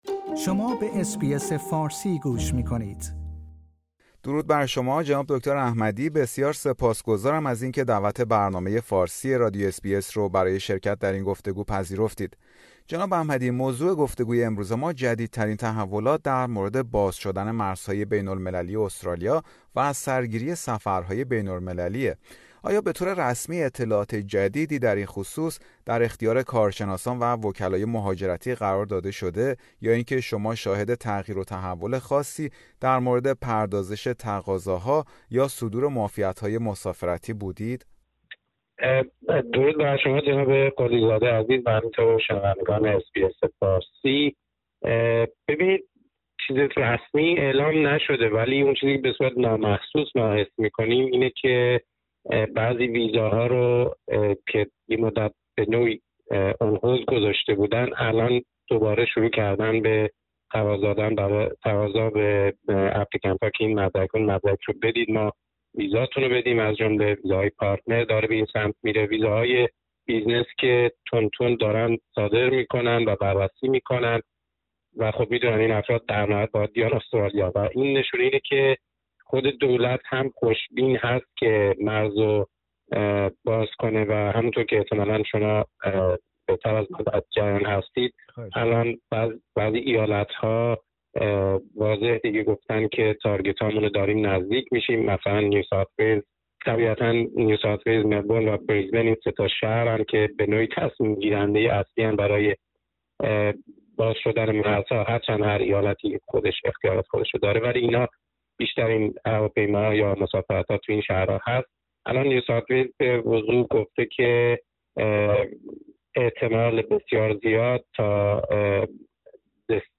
دولت استرالیا برنامه ای آزمایشی را برای کمک به مهاجرت پناهندگان متخصص به این کشور به اجرا گذاشته است. برنامه فارسی رادیو اس بی اس در همین گفتگویی داشته است